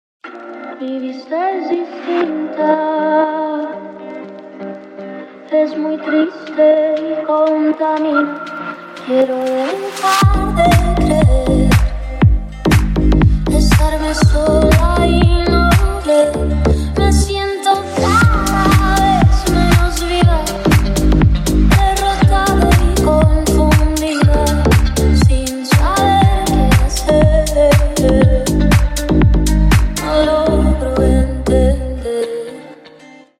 женский голос
Стиль: deep house